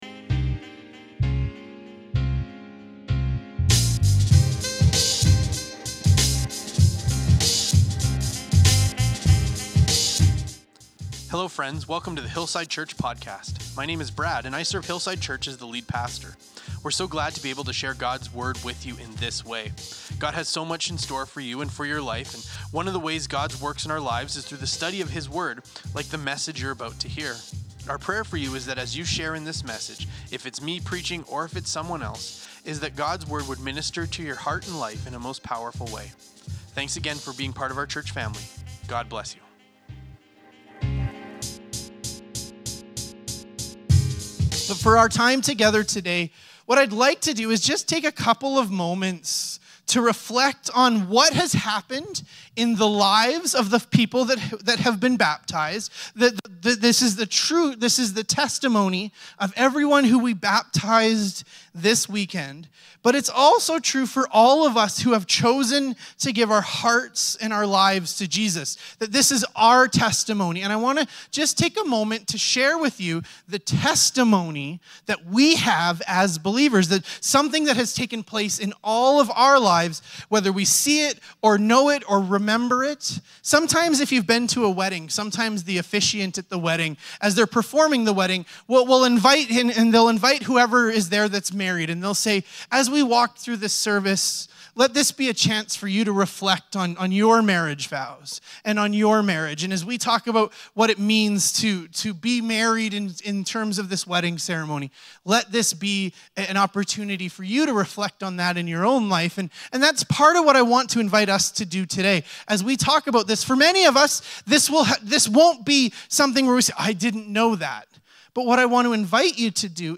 This week was a joyous occasion at Hillside as we celebrated baptisms!